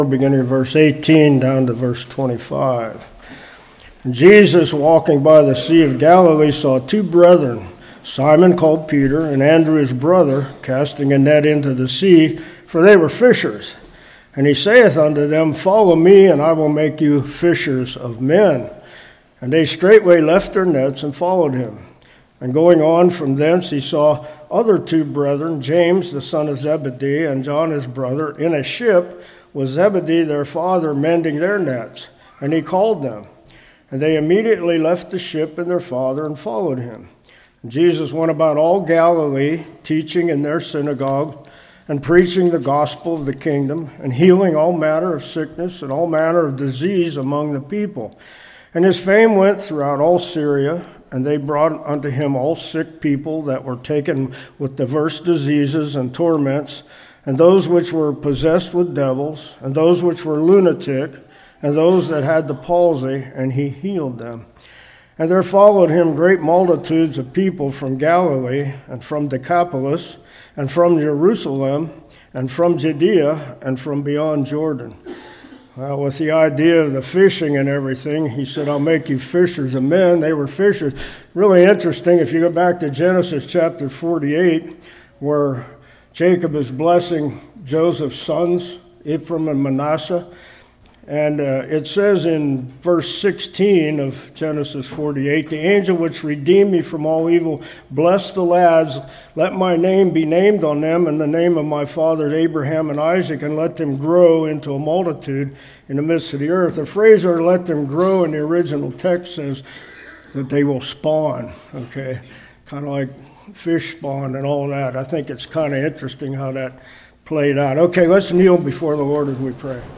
Matthew 4:18-25 Service Type: Revival What Does It Mean To Be A Disciple?